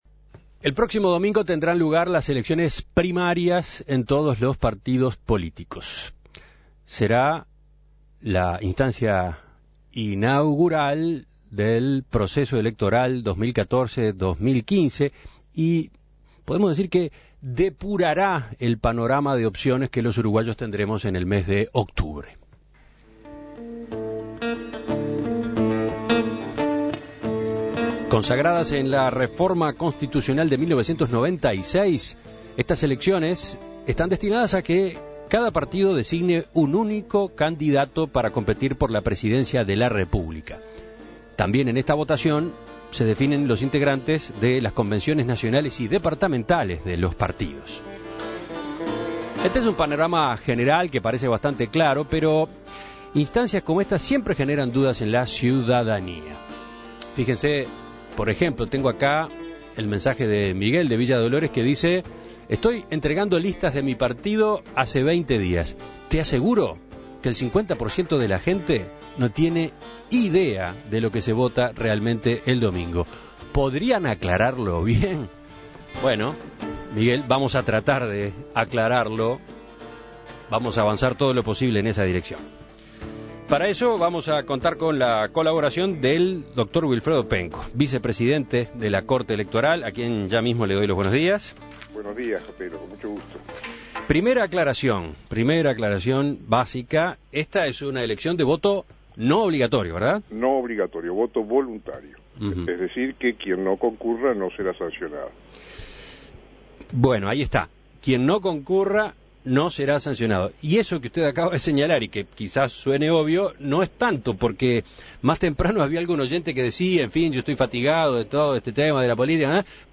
Por este motivo conversó con Wilfredo Penco, vicepresidente de la Corte Electoral. En el diálogo se intentó dejar en claro algunos puntos de esta instancia electoral, como por ejemplo que el voto no es obligatorio, y por lo tanto no hay multas en caso de no concurrir a las urnas.